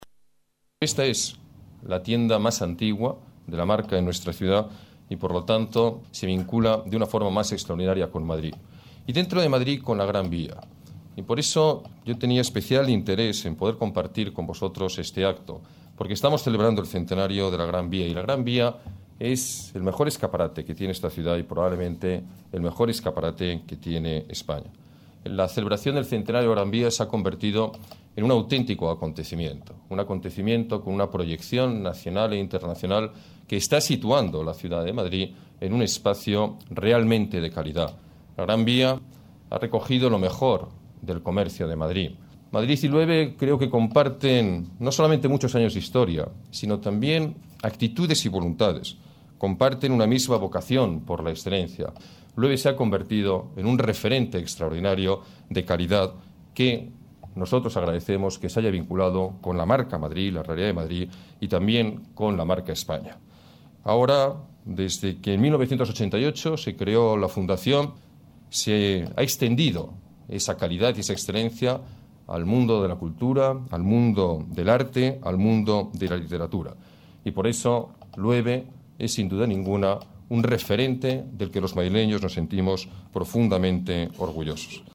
Nueva ventana:Declaraciones del alcalde de la Ciudad de Madrid, Alberto Ruiz-Gallardón: Exposición Loewe